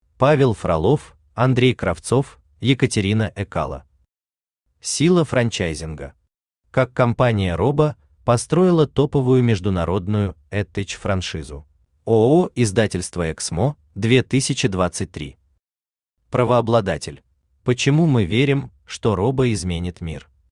Aудиокнига Сила франчайзинга.